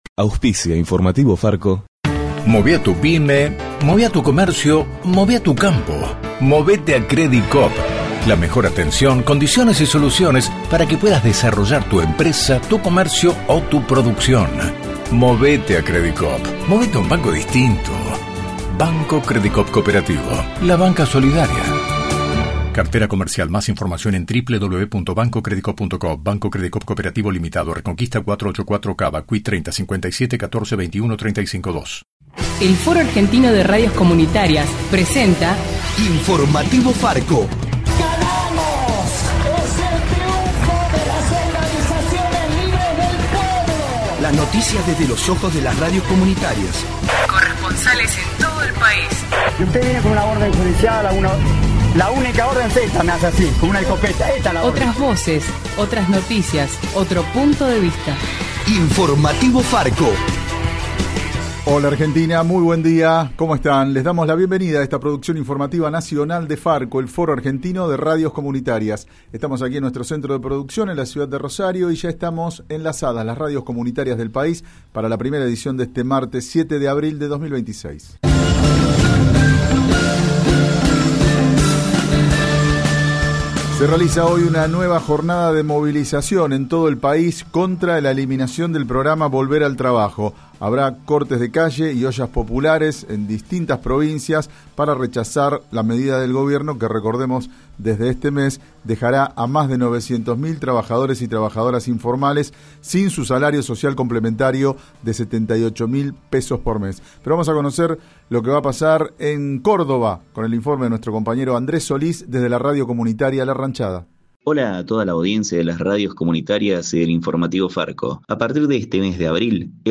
De la mano de FARCO (Foro Argentino de Radios Comunitarias) en Radio Atilra te acercamos el informativo más federal del país.